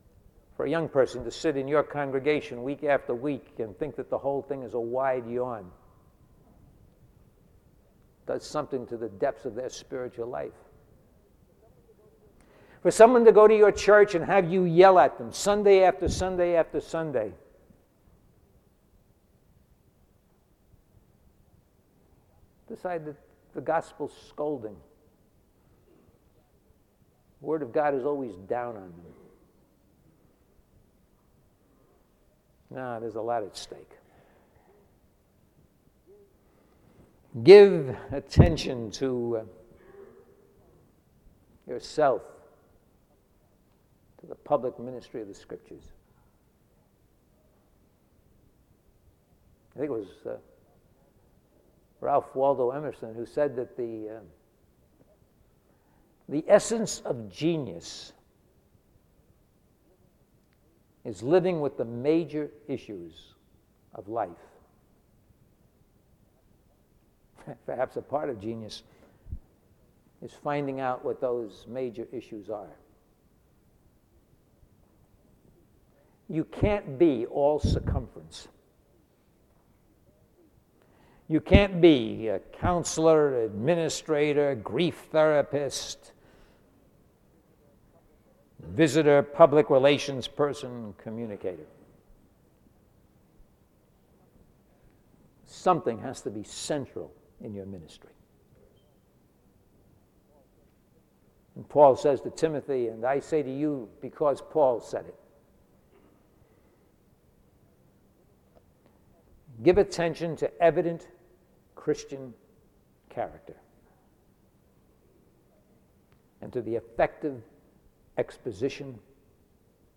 SEBTS Adams Lecture